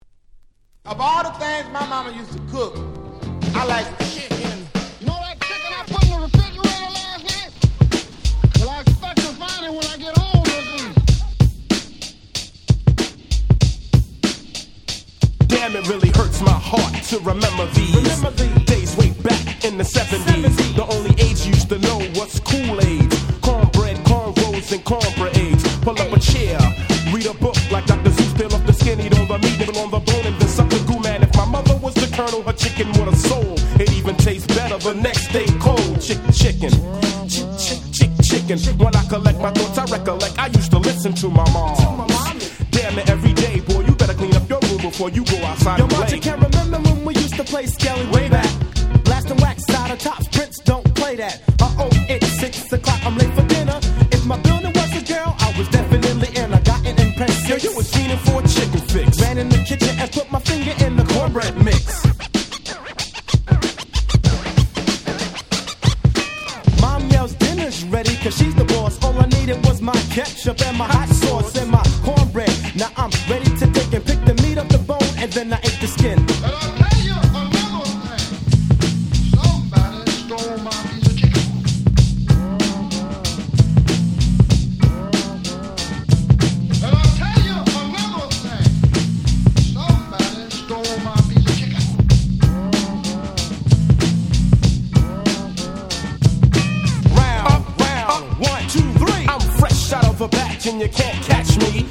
90's Hip Hop Classics !!
90's Boom Bap ブーンバップ